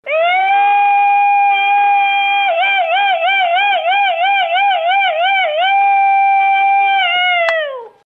sapucay.mp3